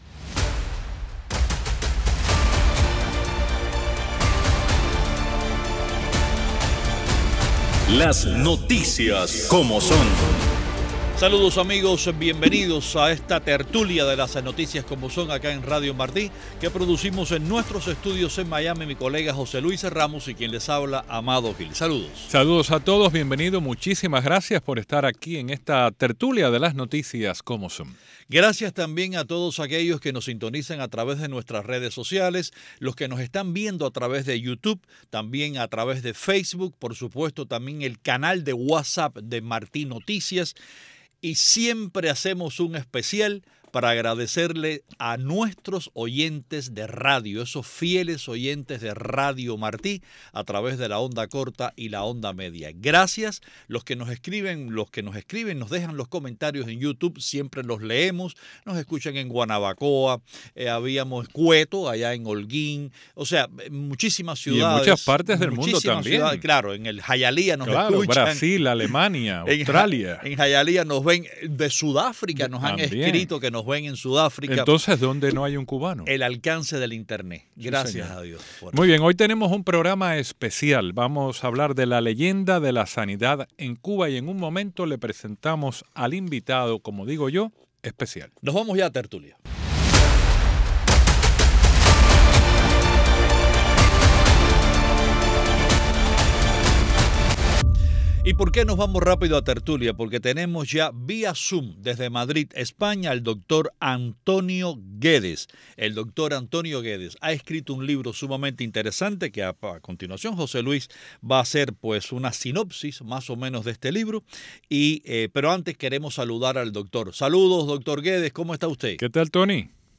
Una discusión a fondo de las principales noticias de Cuba y el mundo